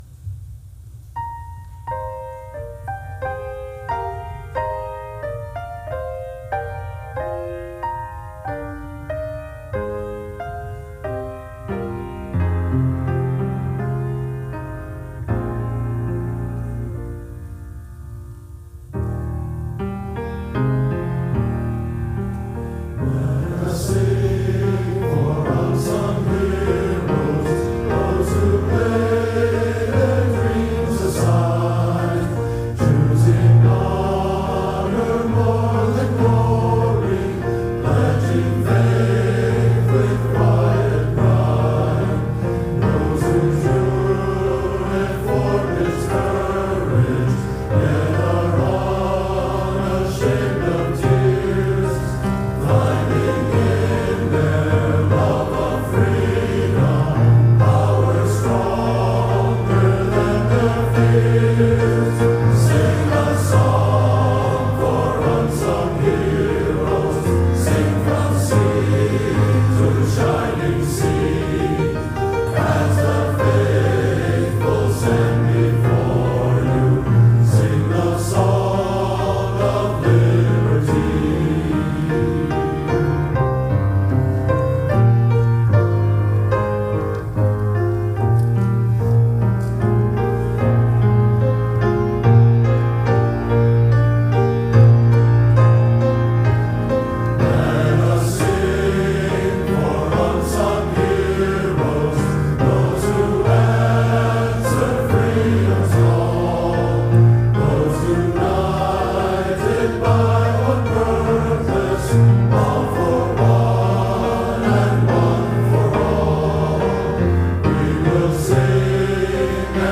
First Congregational Church Of Southington, Connecticut - April 22, 2023